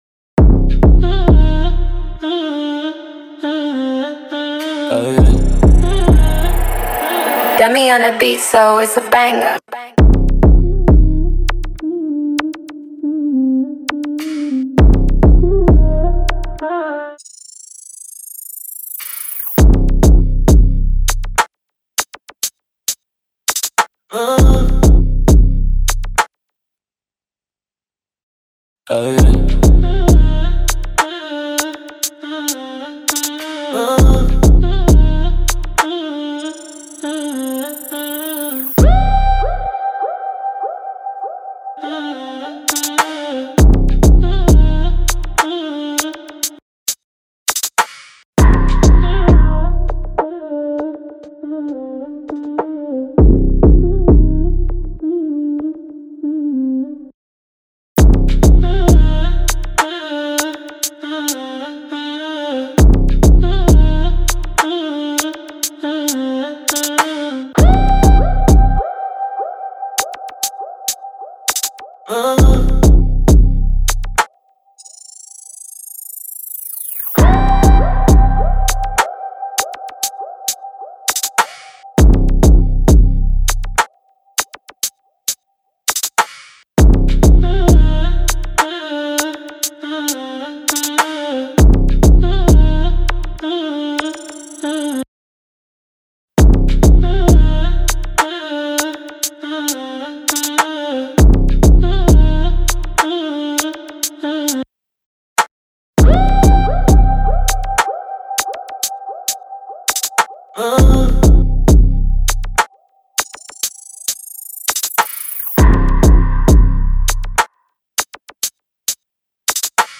official instrumental
Hip-Hop Instrumentals